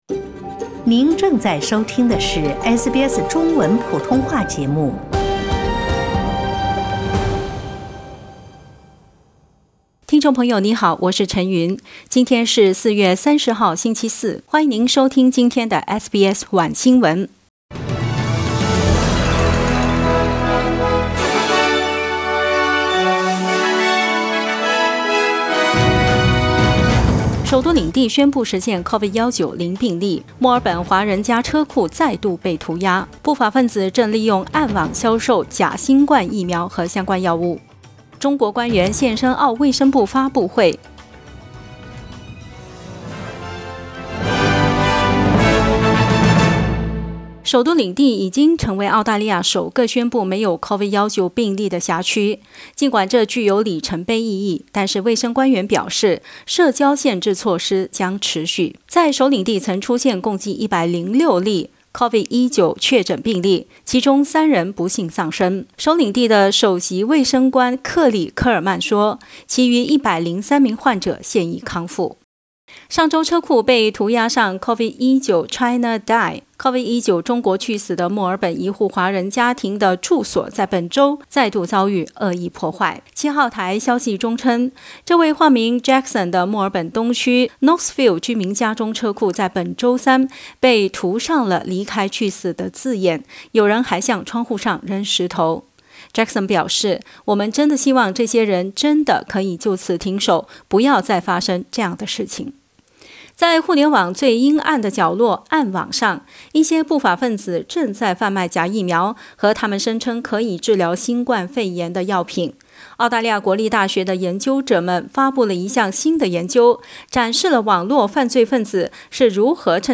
SBS晚新闻（4月30日）